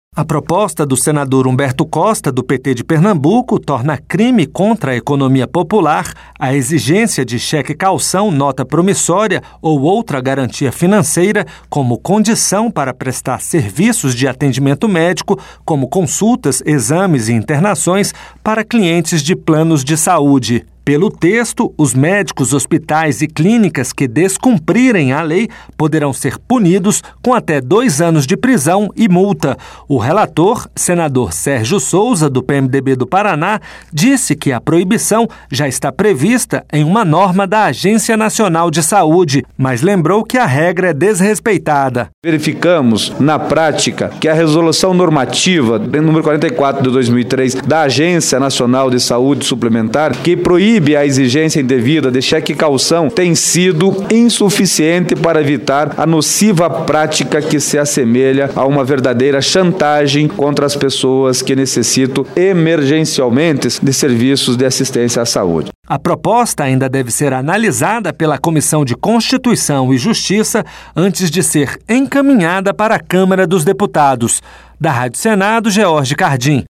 O relator, senador Sérgio Souza, do PMDB do Paraná, disse que a proibição já está prevista em uma norma da Agência Nacional de Saúde, mas lembrou que a regra é descumprida na prática.